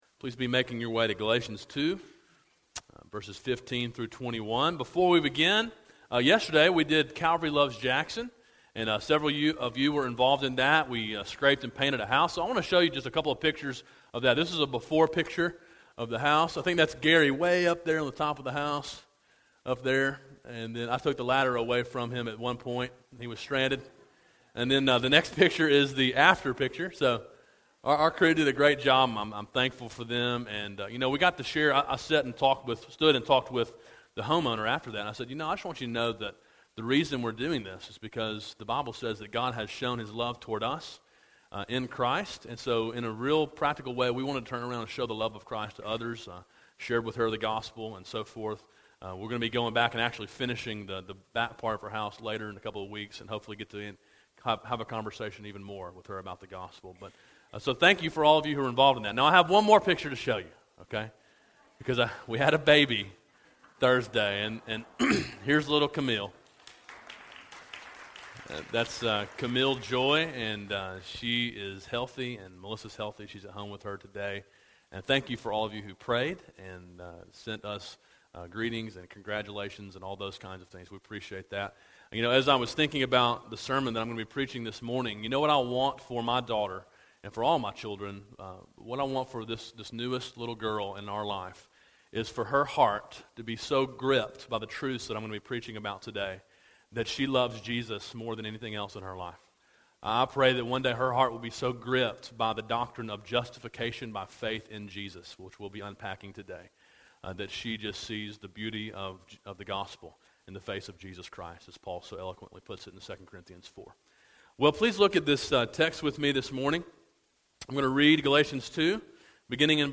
A sermon in a series titled Freedom: A Study of Galatians.